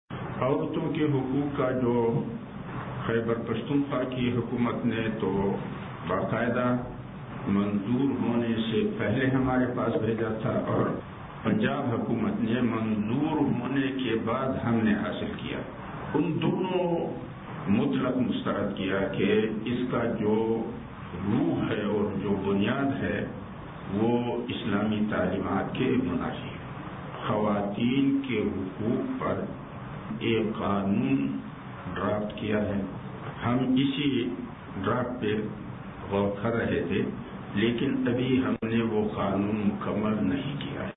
سنیے 7 بجے کے نیوز بُلیٹن میں